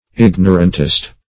Search Result for " ignorantist" : The Collaborative International Dictionary of English v.0.48: Ignorantist \Ig"no*rant*ist\, n. One opposed to the diffusion of knowledge; an obscurantist.
ignorantist.mp3